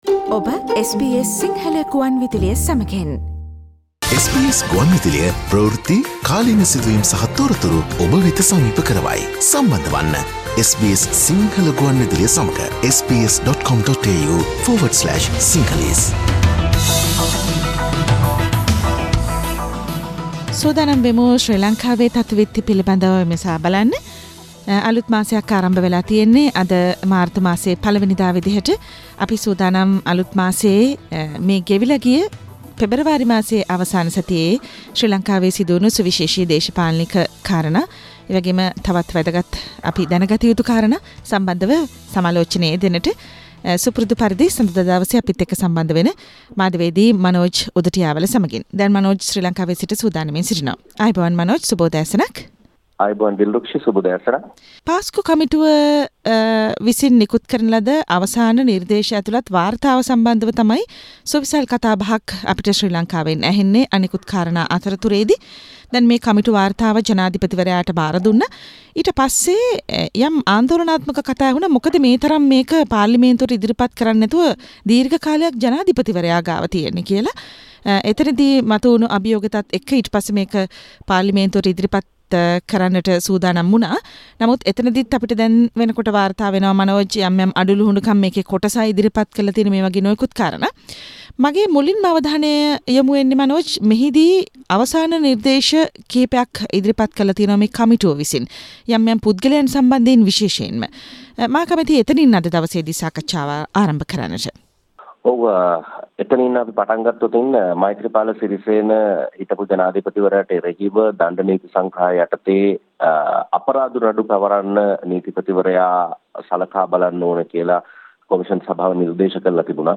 Most of political parties are not accepting the Easter committee report and what happened in Geneva this time? Sri Lankan news wrap